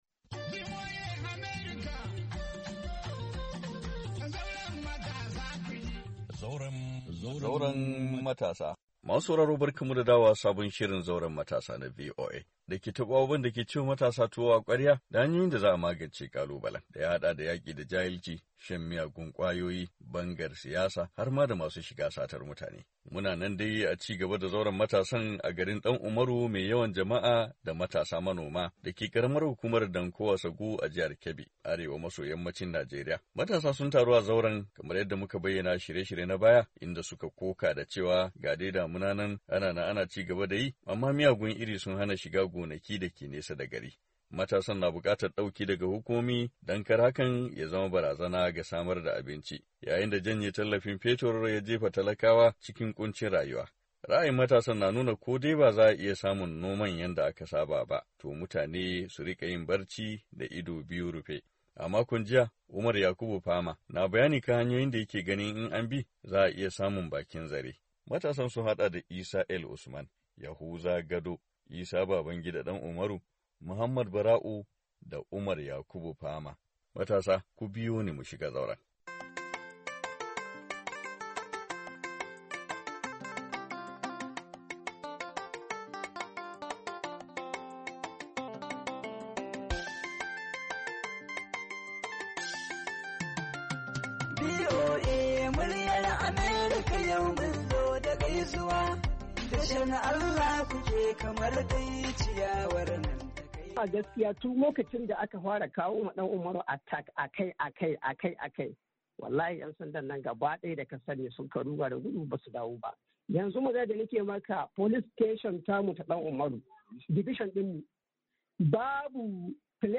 Ci gaban Zauren Matasa a garin Dan Umaru mai yawan jama'a da matasan manoma da ke karamar hukumar Danko Wasagu a jihar Kebbi arewa maso yammacin Najeriya. Matasa sun taru a zauren inda suka koka da cewa ga dai damuna ta kankama amma miyagun iri sun hana shiga gonaki da ke nesa da gari....